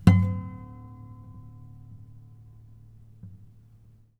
harmonic-06.wav